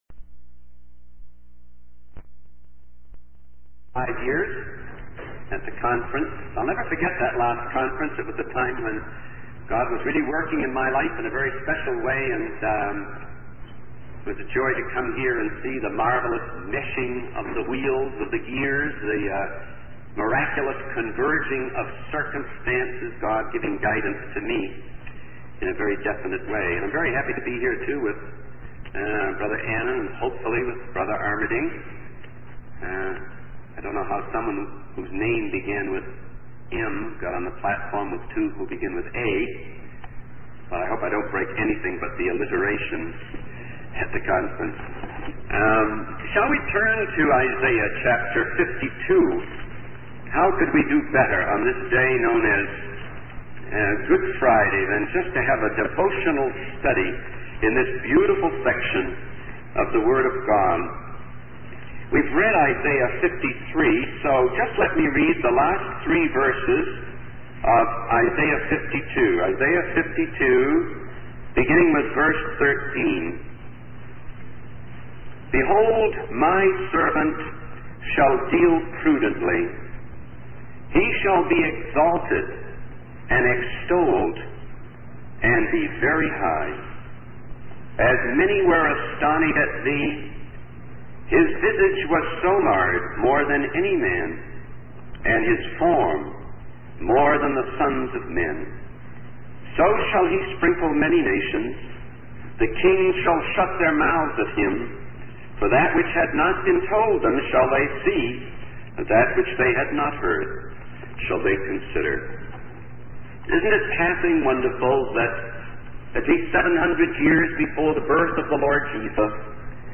In this sermon, the speaker reflects on a past conference where God was working in their life in a special way.